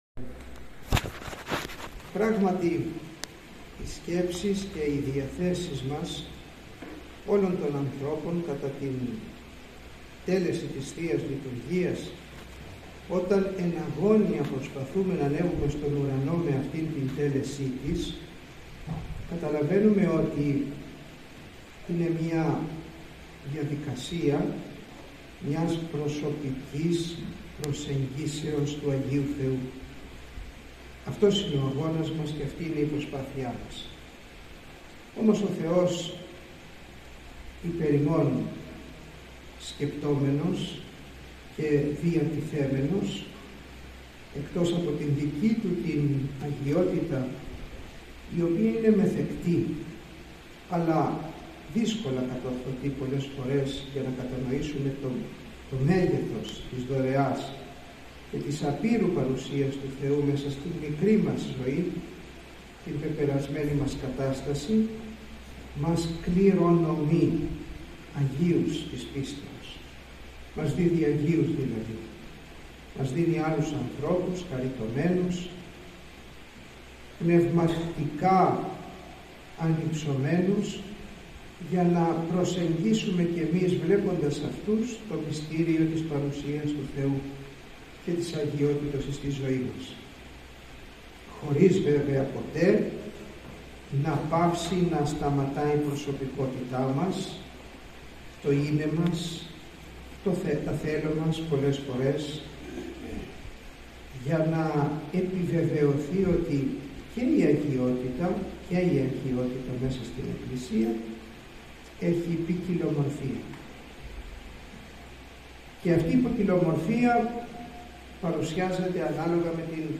Ο Σεβασμιώτατος Μητροπολίτης Θεσσαλιώτιδος και Φαναριοφερσάλων κ. Τιμόθεος, το εσπέρας της Τετάρτης 10 Ιουλίου 2024 χοροστάτησε στην ακολουθία του Εσπερινού στην Ιερά Μονή Παναγίας «Πελεκητής». Κατά τη διάρκεια της Ακολουθίας, τέλεσε την μοναχική κουρά Δοκίμου της Ιεράς Μονής.